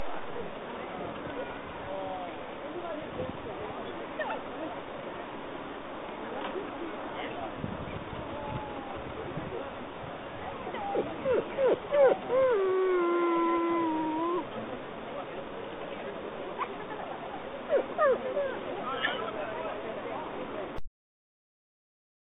Tävlingen gick av stapeln på Norrköpings Brukshundklubb.
Gnyendet i bakgrunden kommer från Chili, som inte alls ville ligga stilla hos husse när Kenzo och jag var inne på planen...